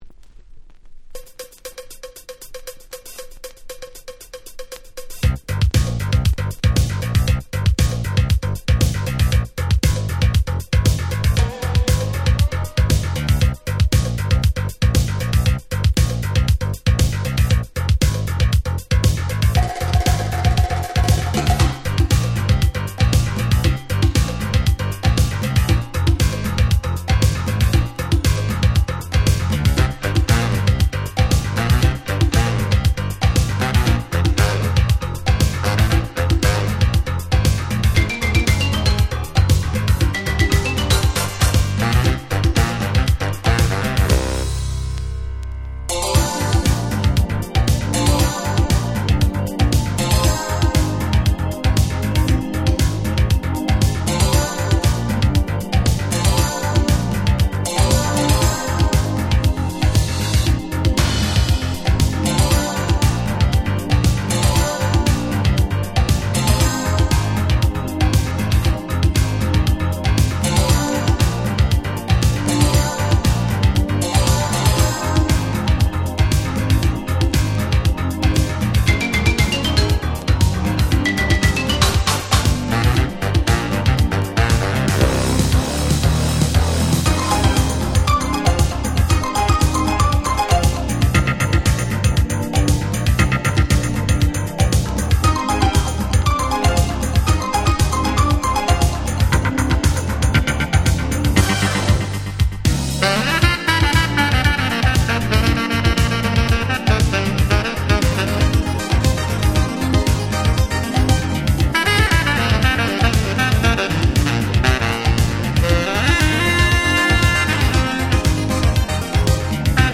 87' Nice Synth Pop !!
Instでの収録ですがそれで良いんです！
もう超Jazzyで最高のSmooth Disco !!